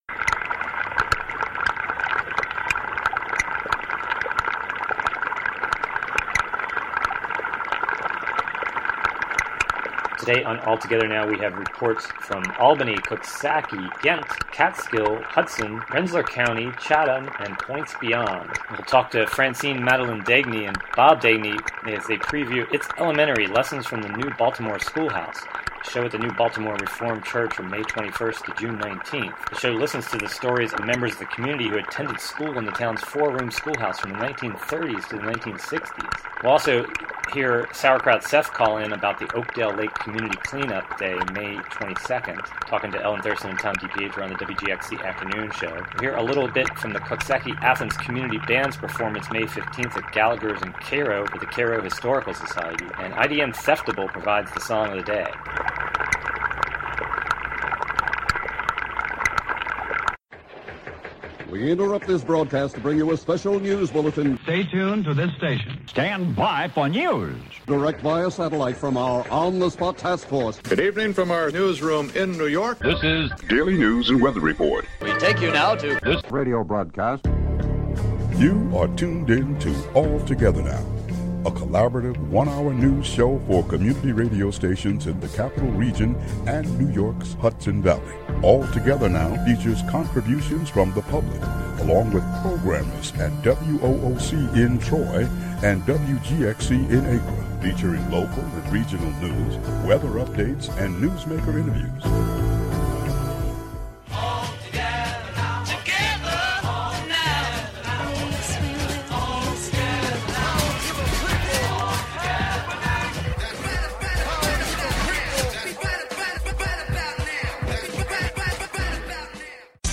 Also, the show listens to the Coxsackie-Athens Community Band's performance May 15 at Gallagher's in Cairo for the Cairo Historical Society.